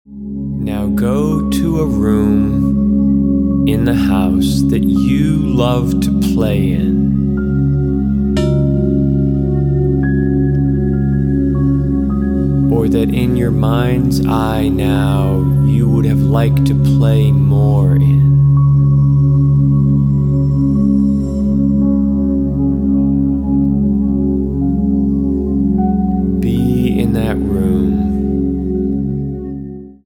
What+Do+You+Want+Meditation.mp3